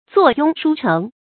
坐拥书城 zuò yōng shū chéng
坐拥书城发音